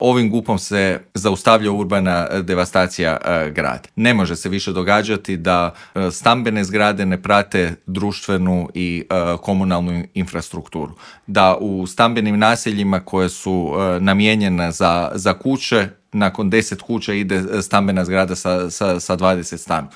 O ovim i drugim gradskim temama u Intervjuu Media servisa smo razgovarali s predsjednikom Gradske skupštine iz redova SDP-a, Matejem Mišićem.